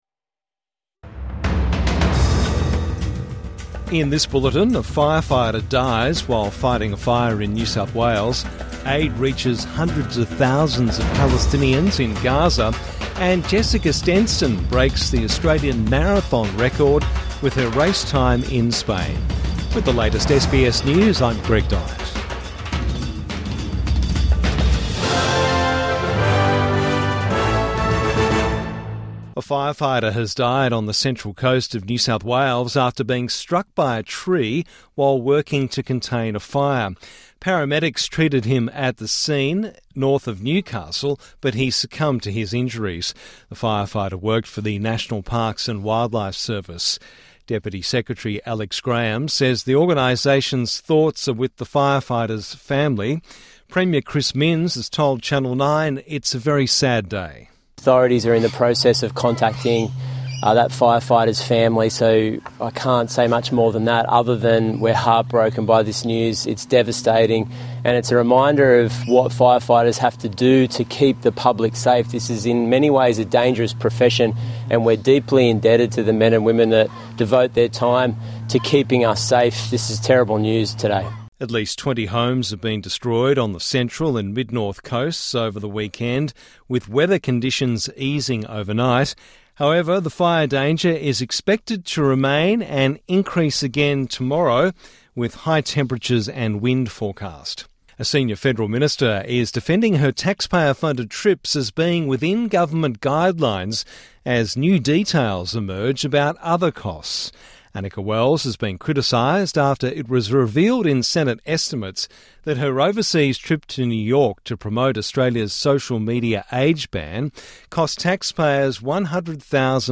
Firefighter killed in New South Wales | Midday News Bulletin 8 December 2025